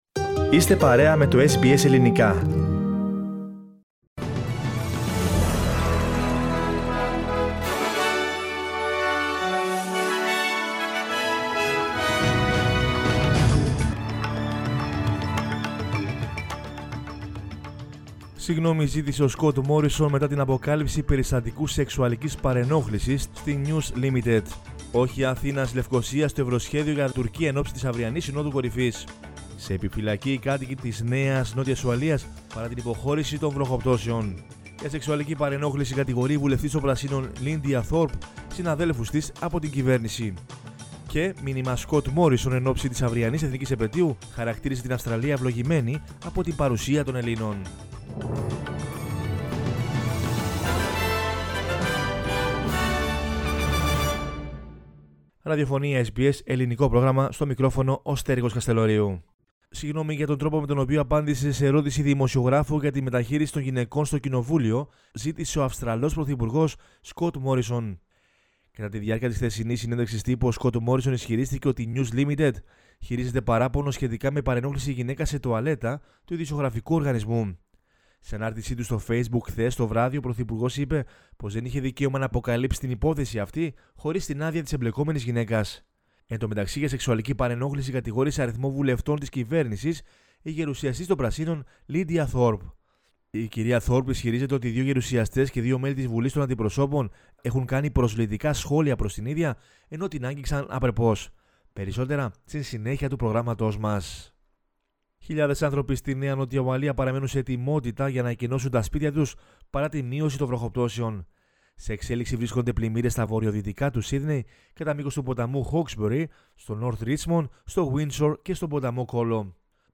News in Greek from Australia, Greece, Cyprus and the world is the news bulletin of Wednesday 24 March 2021.